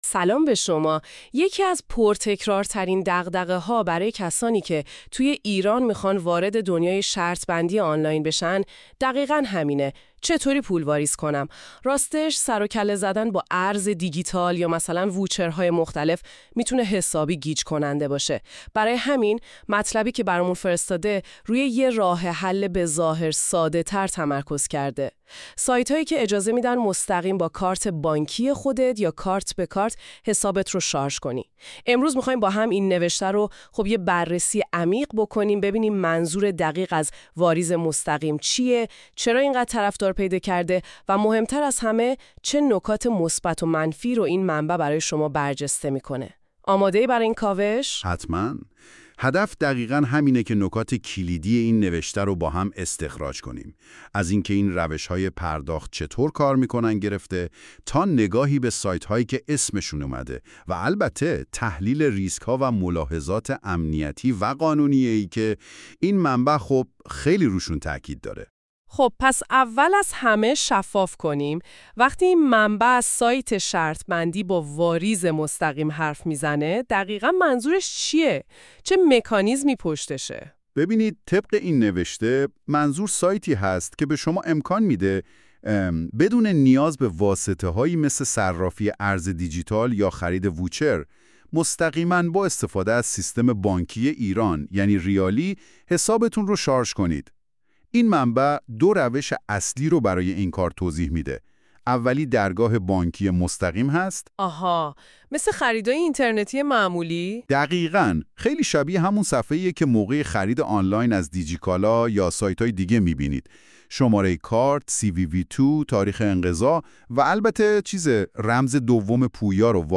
در این پادکست، خلاصه مقاله سایت شرط بندی با واریز مستقیم را به‌صورت صوتی می‌شنوید. در این اپیزود به بررسی سایت‌های معتبر، امنیت تراکنش‌ها، سرعت واریز و معیارهای انتخاب سایت مطمئن پرداخته می‌شود.